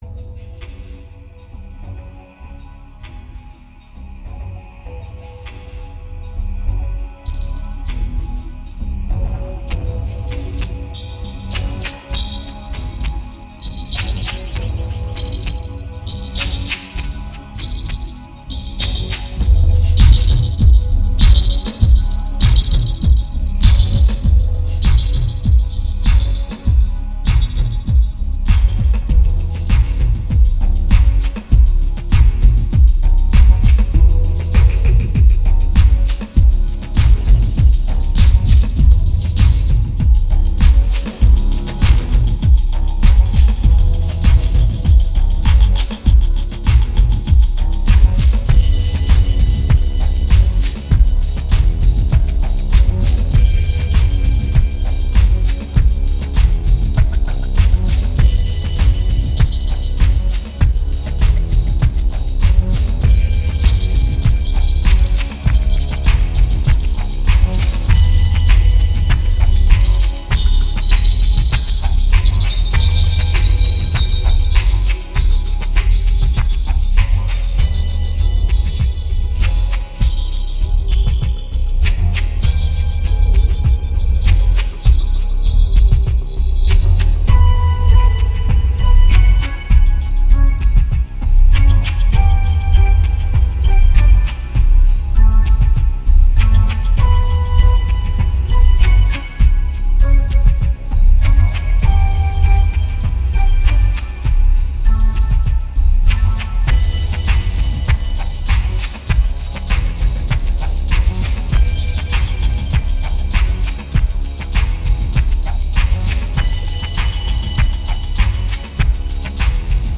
Tense and diverse